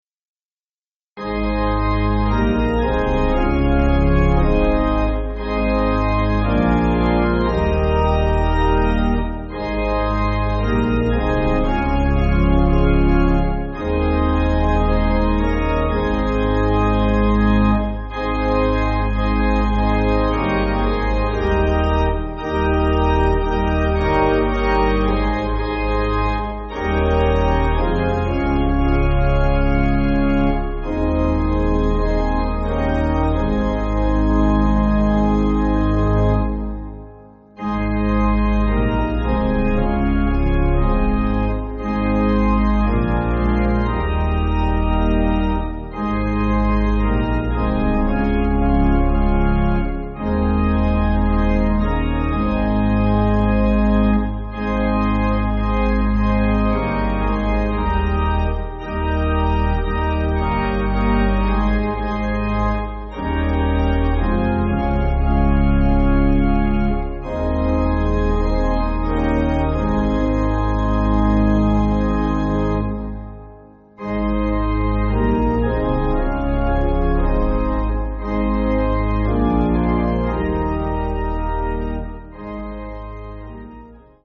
(CM)   6/Ab